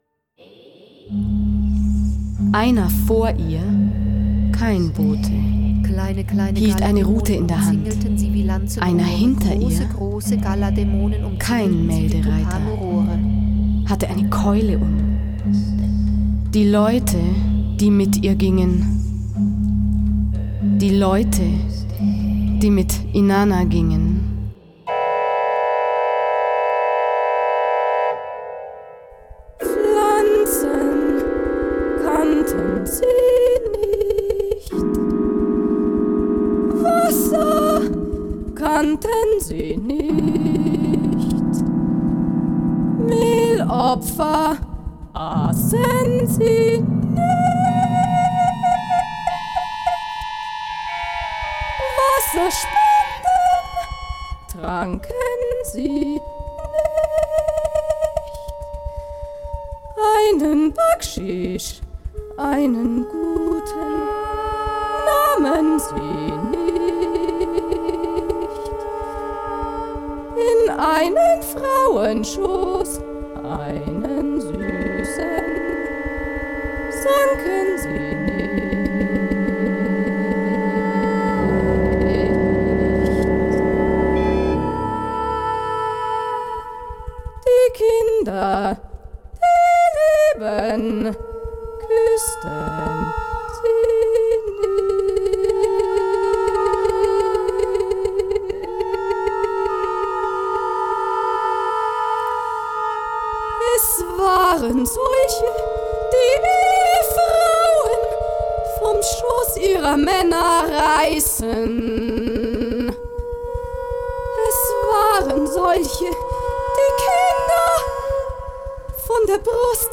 Orgel
Percussion
Violoncello
Flöten
Klarinetten
Uraufführung in der Kreuzkirche München 2002